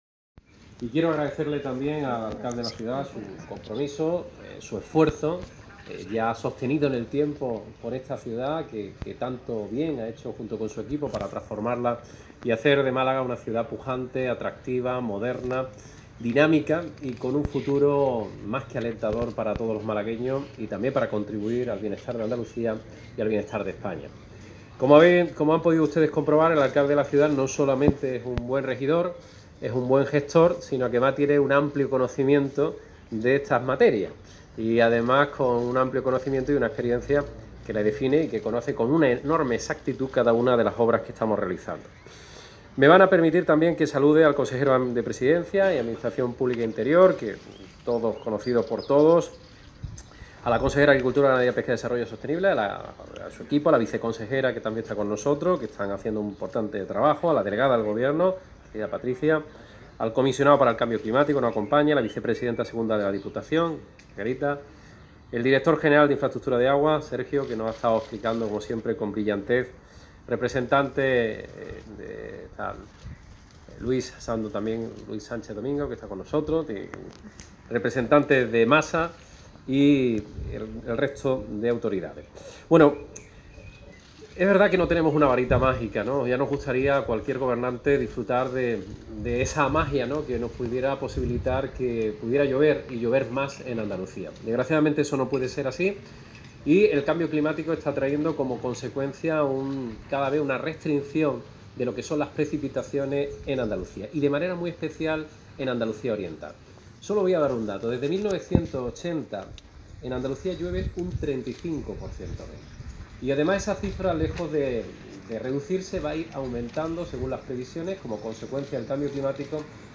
Durante su intervención en la jornada virtual ‘Málaga tecnológica: La gran oportunidad’, organizada por la Diputación de Málaga y Diario SUR, Bendodo ha explicado que esta Agencia, que echará a andar en los próximos días, tiene tres objetivos prioritarios: eliminar la brecha digital, apostar por la administración digital y agilizar los procesos en las relaciones de los ciudadanos con las instituciones, y prestar los servicios a la ciudadanía de forma más eficaz.